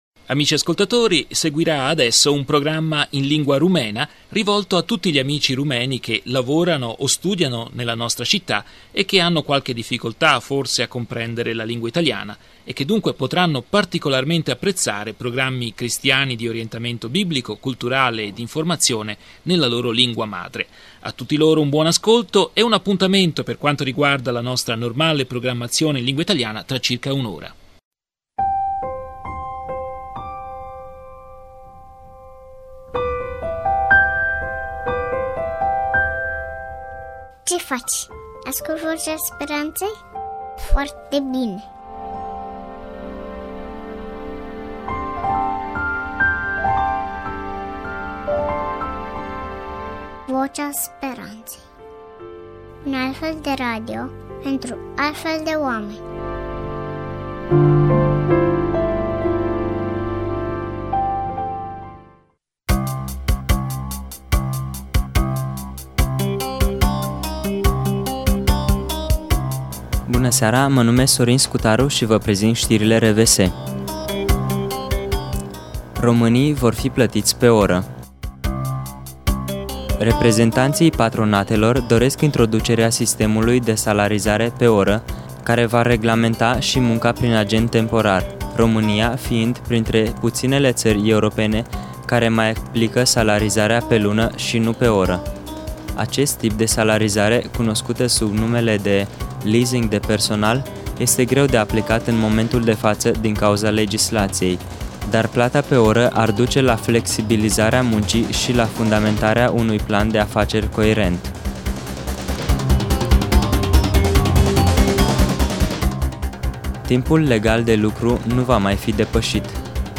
Programma in lingua rumena di informazione, cultura e riflessione cristiana a cura della redazione rumena di radio voce della speranza, trasmessa l’11 ottobre 2007.